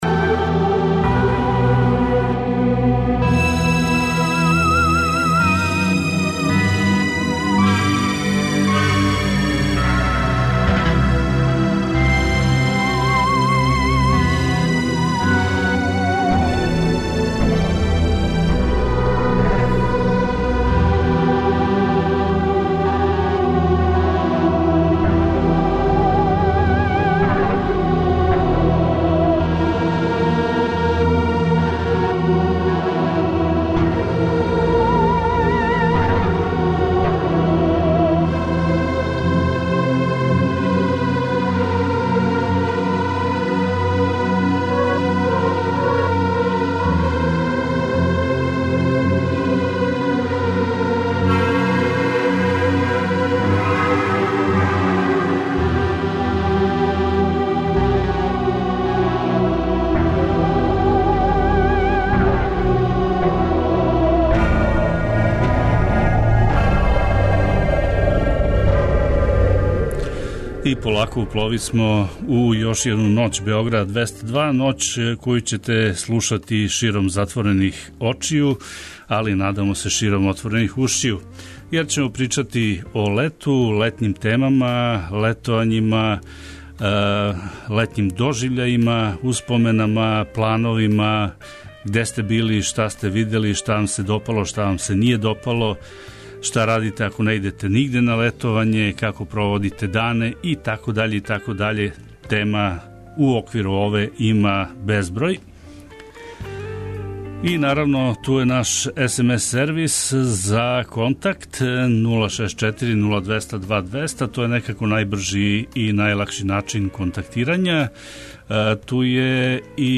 Ноћни програм Београда 202.